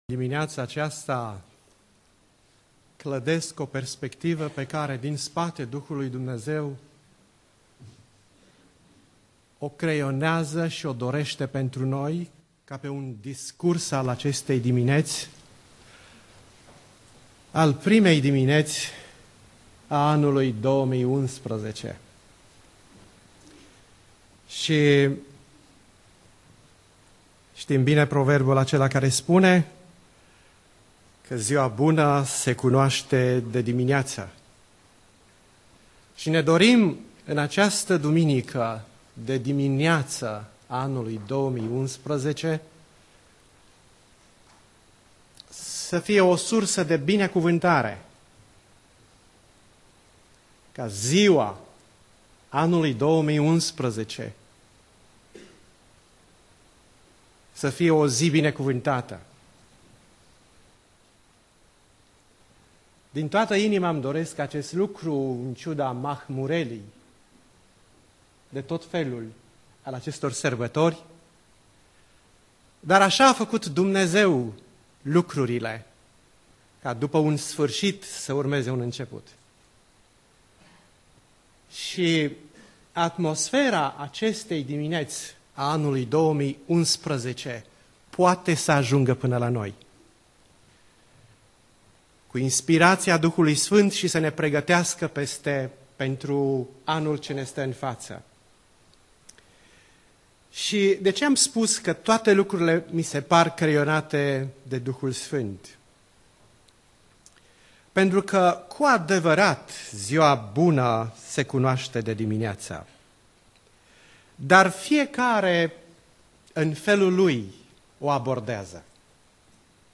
Predica Aplicatie - 1 Imparati Cap 8